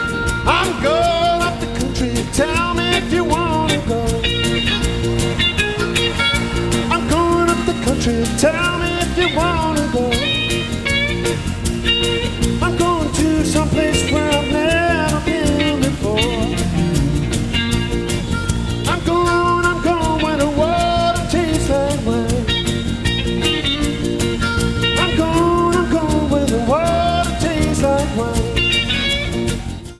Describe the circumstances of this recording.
Excellent sound quality.